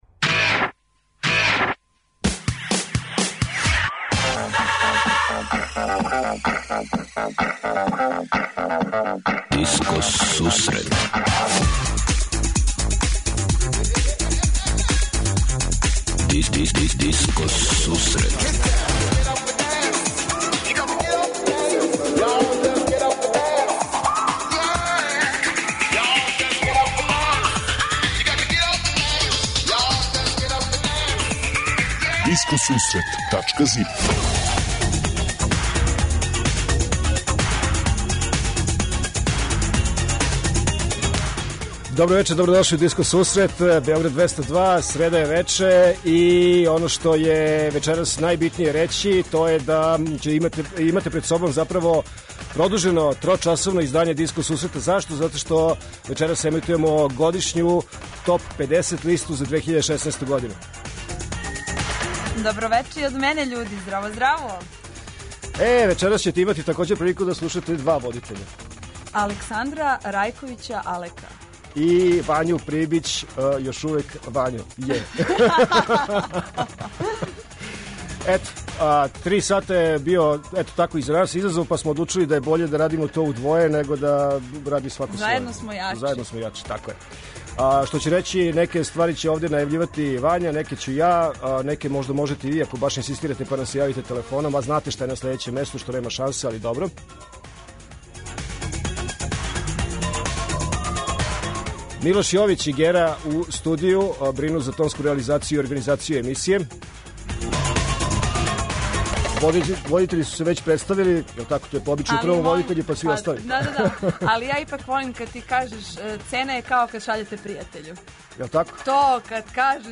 Топ листа 50 највећих светских диско хитова у 2016.-ој години. Специјално трочасовно, годишње издање Диско Сусрета!
преузми : 64.75 MB Discoteca+ Autor: Београд 202 Discoteca+ је емисија посвећена најновијој и оригиналној диско музици у широком смислу, укључујући све стилске утицаје других музичких праваца - фанк, соул, РнБ, итало-диско, денс, поп.